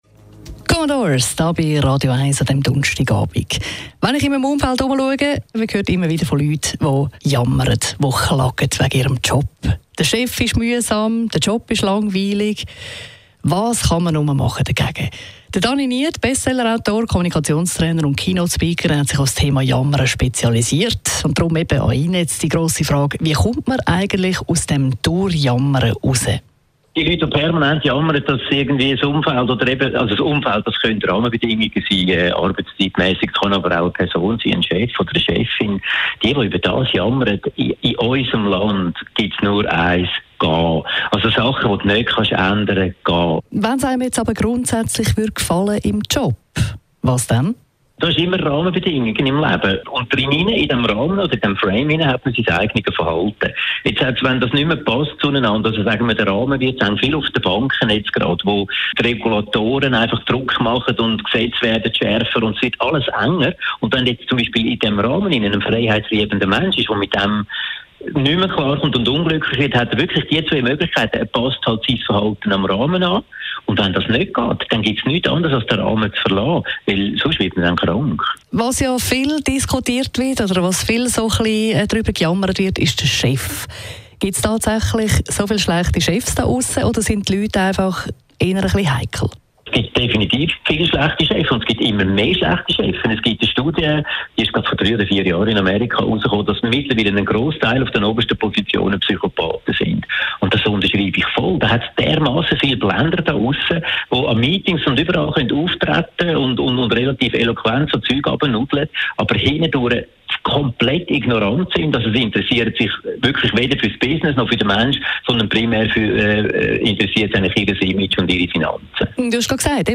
Anti-Jammer-Interview auf Radio1